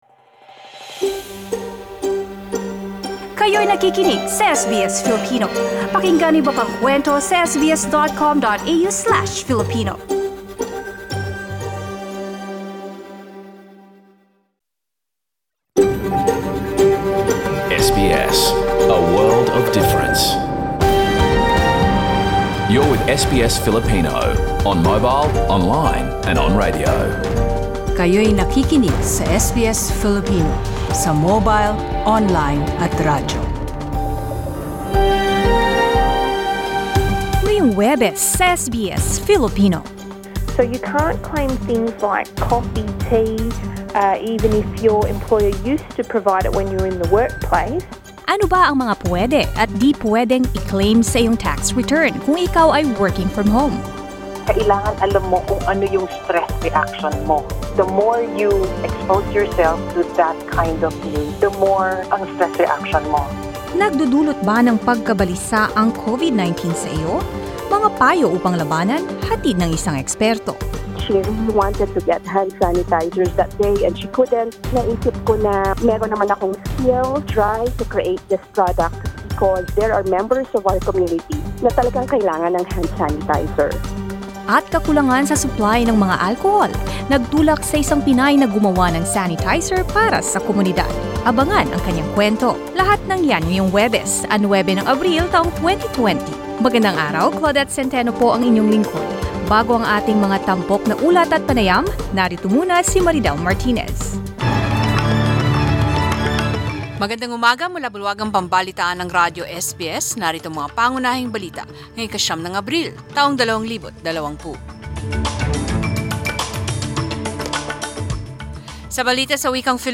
News in Filipino 9 April 2020